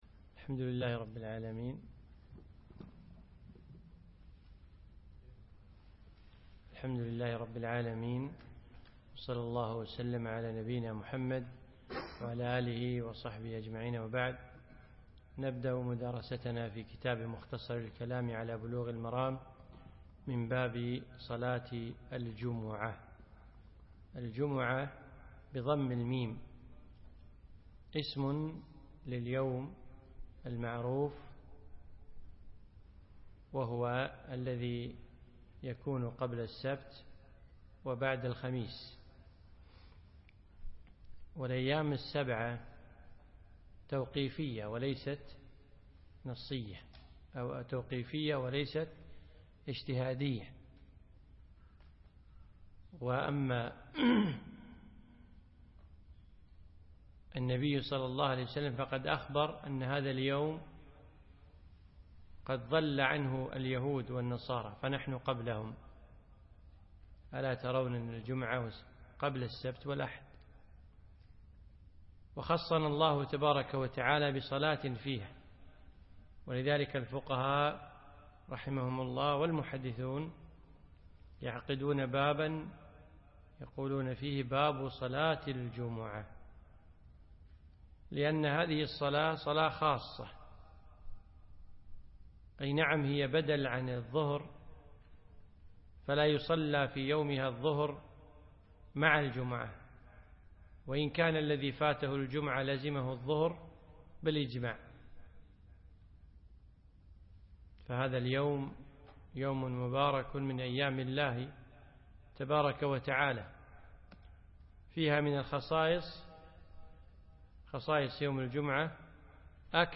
الدرس السابع عشر